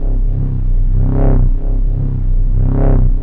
teleport_idle.ogg